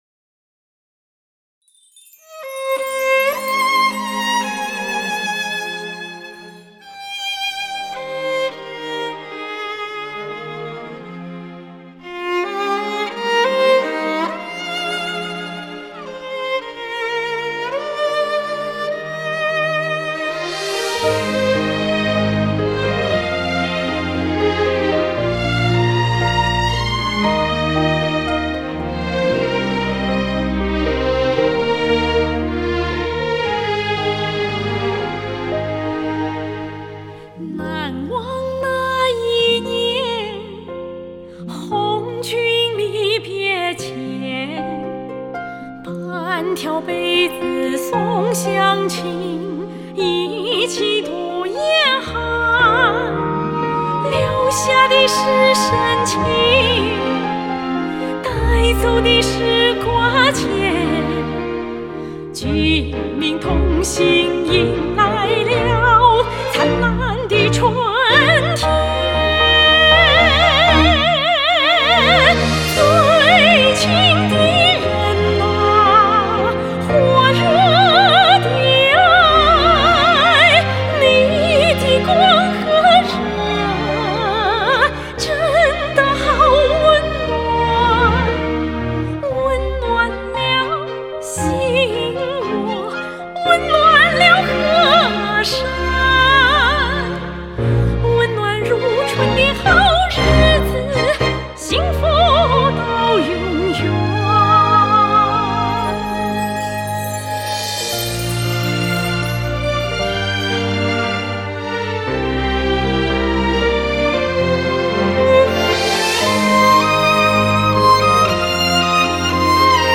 歌曲开头一小段悠扬的小提琴，把人立即带入了一种暖意融融的情境。